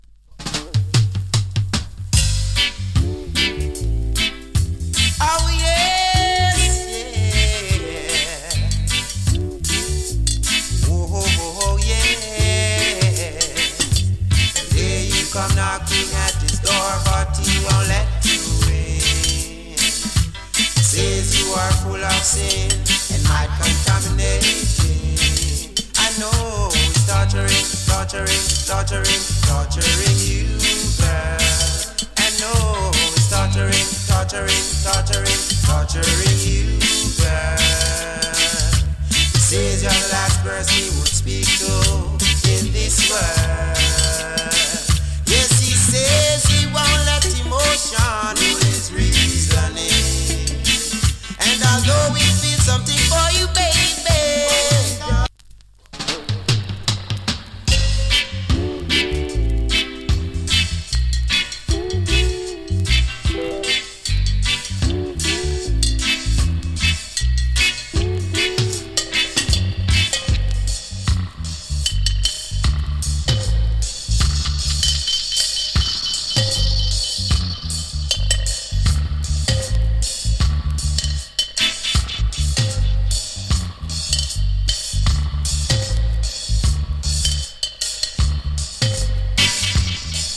チリ、ジリノイズわずかに有り。
75年録音の FINE ROOTS VOCAL ! 70年代の２ndプレスと思われます。